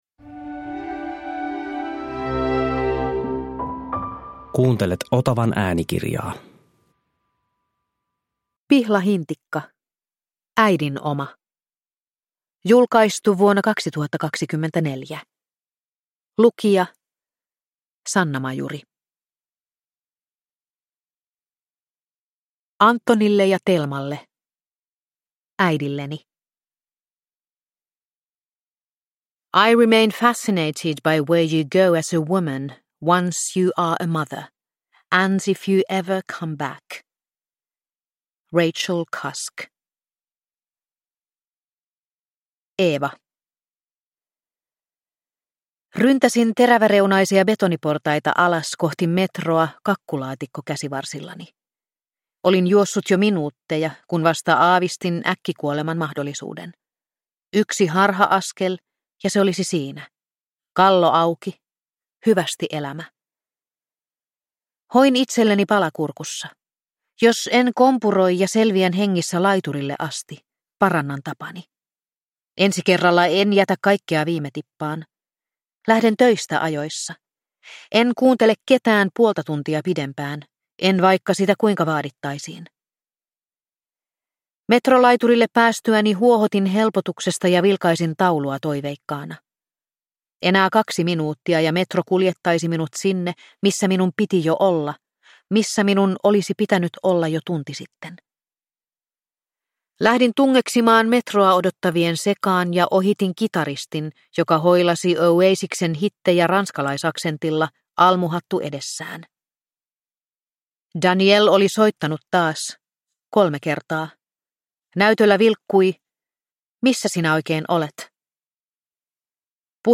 Äidin oma – Ljudbok